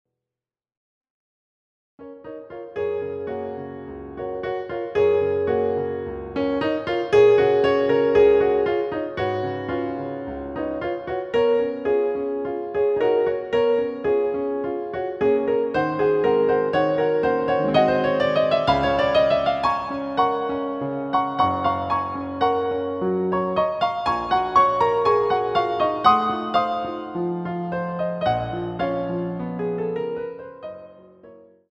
CD quality digital audio
using the stereo sampled sound of a Yamaha Grand Piano.